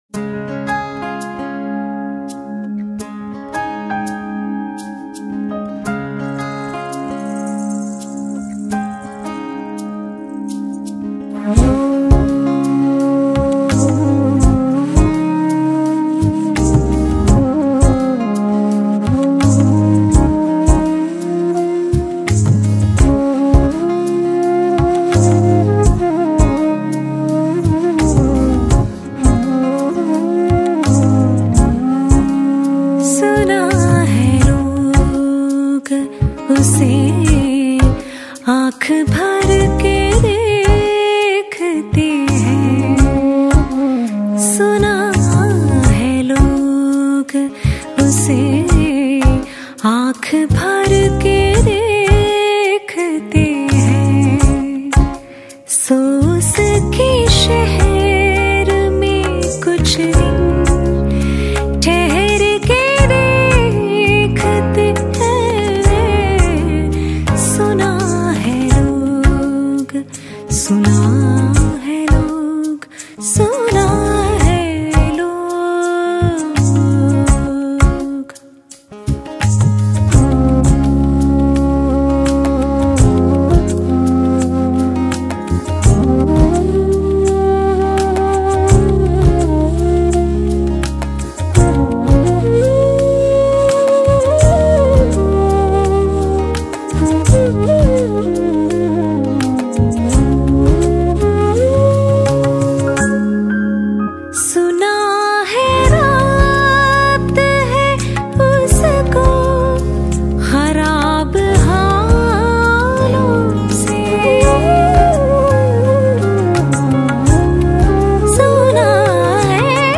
Ghazals